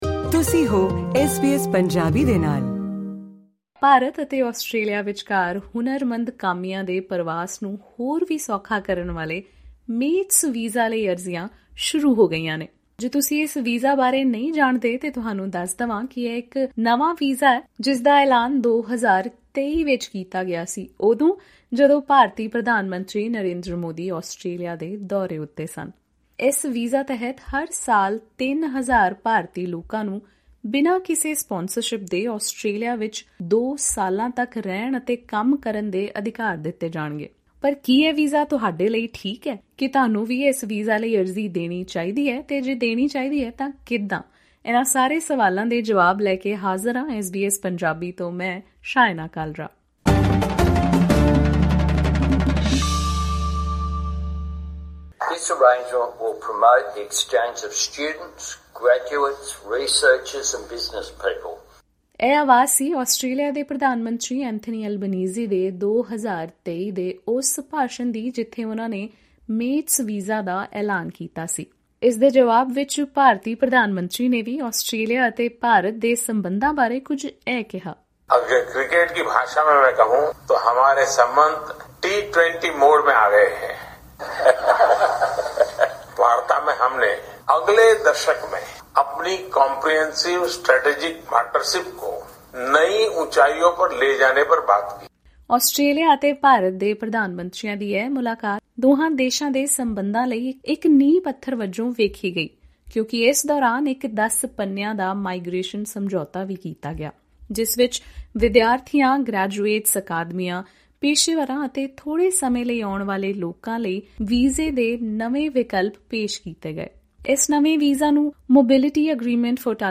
This visa allows graduates and young professionals to come to Australia on a two-year visa without sponsorship. For more information about this less popular visa, listen to this conversation between SBS Punjabi and migration agent